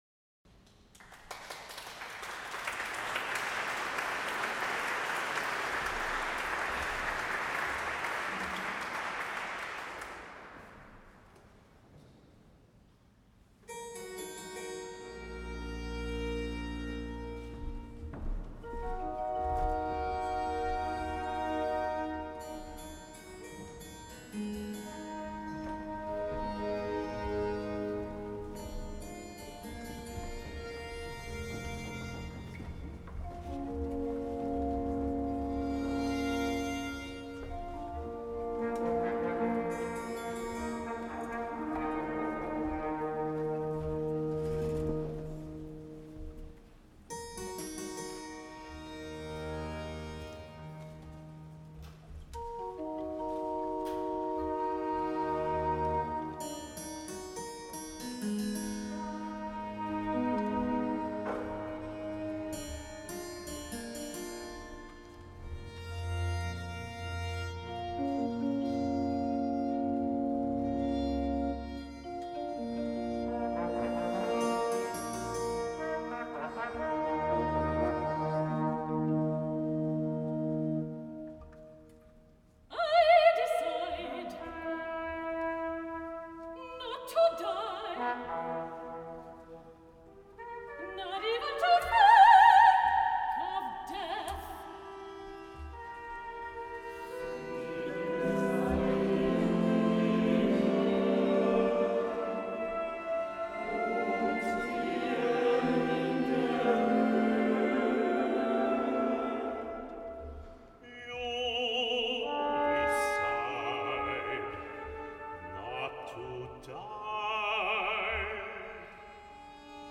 Musica salutaris | 01.09.2019 Audioaufnahme der Aufführung der Musica salutaris